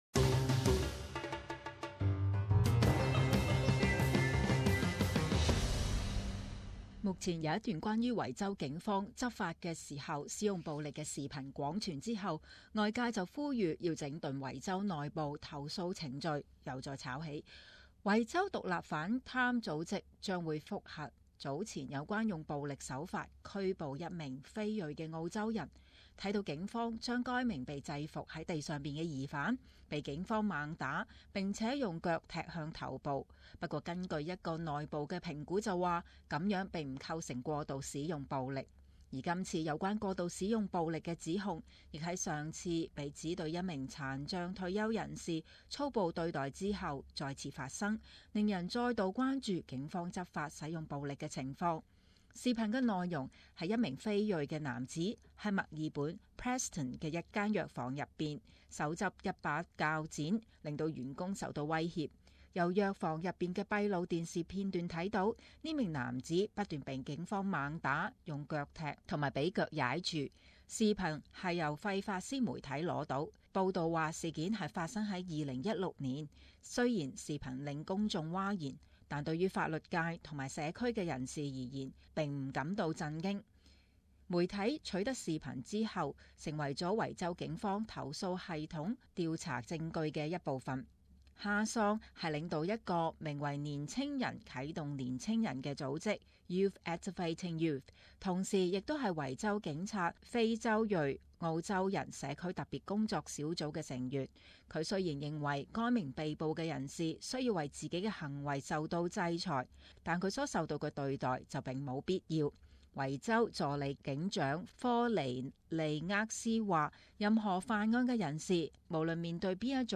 【時事報導】警方暴力執法是否合宜？